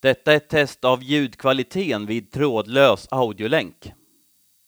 Spelade upp det FM-modulerade över dioderna, och demodulerade det som mottagardioden snappade upp.
Över FM-diod-länk:
FM-MOD.wav